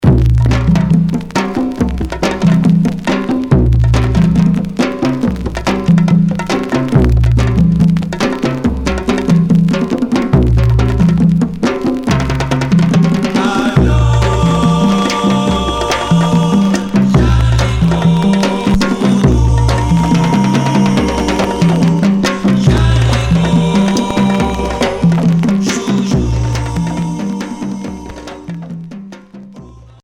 Groove pop psychédélique